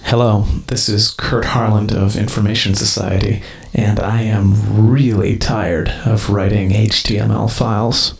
Greeting.wav